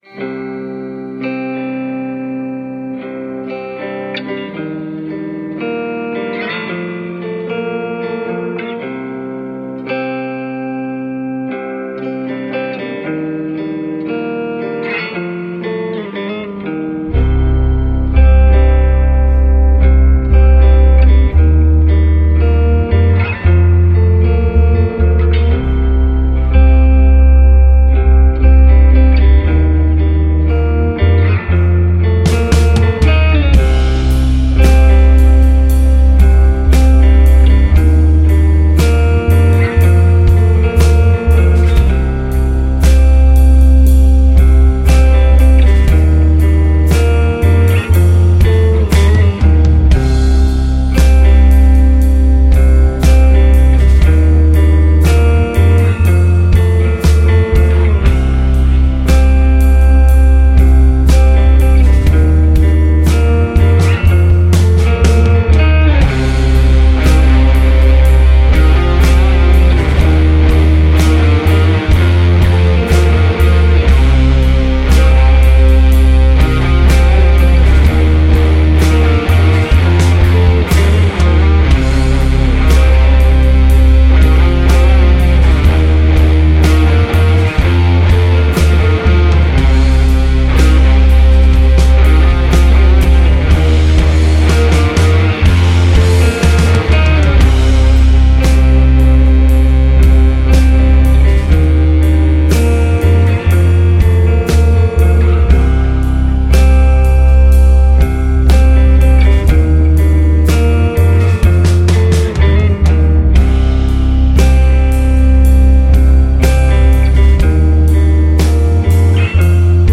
sludge rock trio